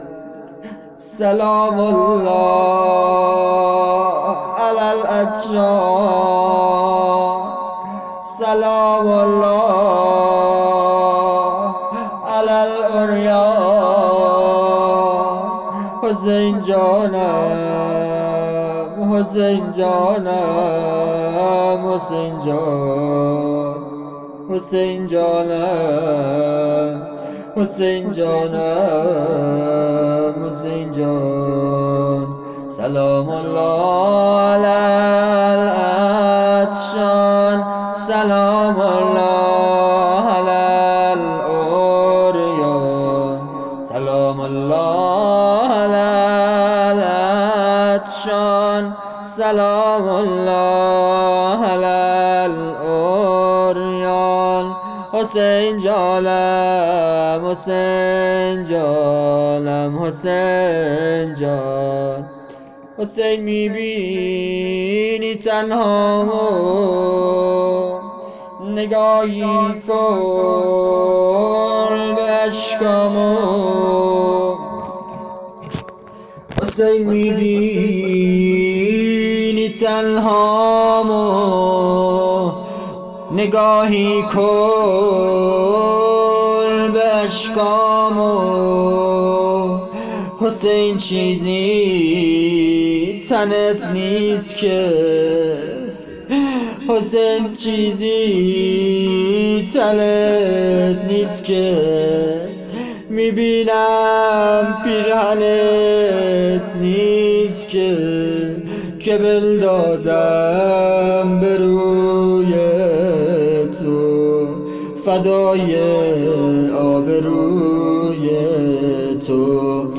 سبک روضه